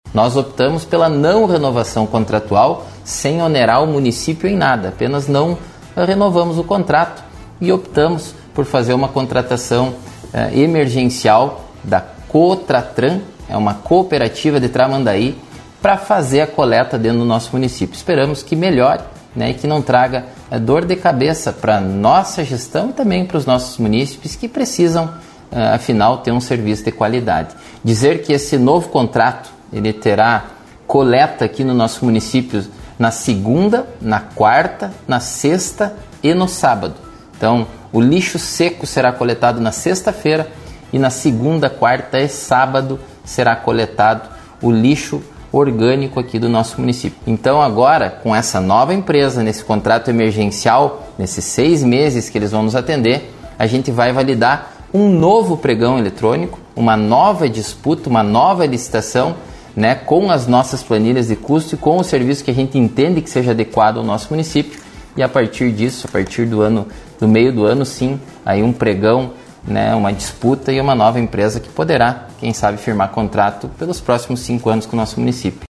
Ele explica os próximos passos para a solução da questão: